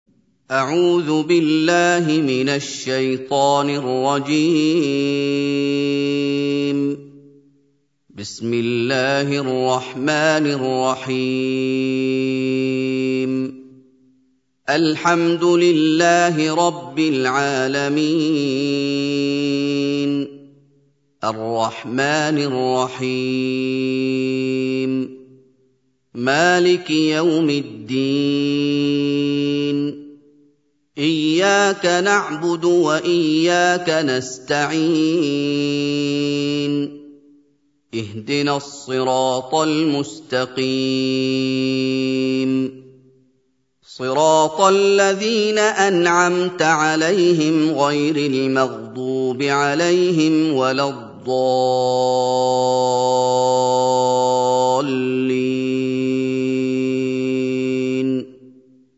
سورة الفاتحة | القارئ محمد أيوب